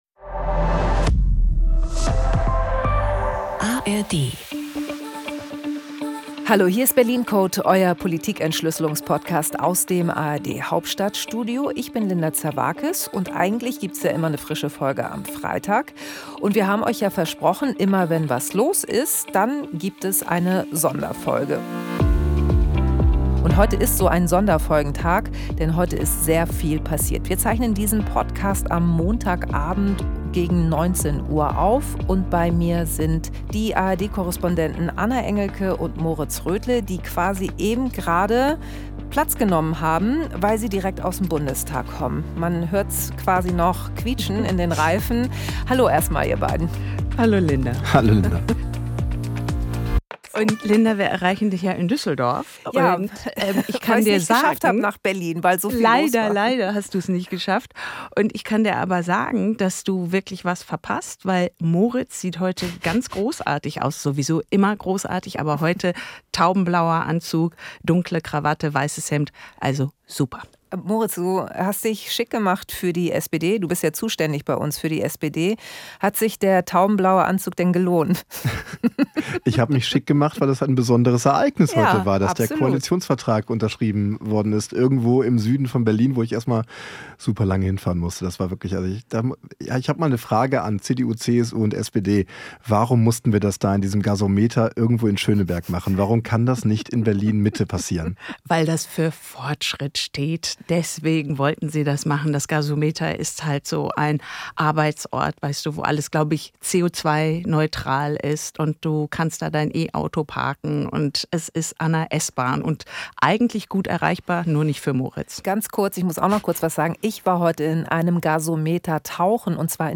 Linda Zervakis schaut mit den ARD-Korrespondentinnen und -korrespondenten jede Woche hinter die Kulissen der Bundespolitik.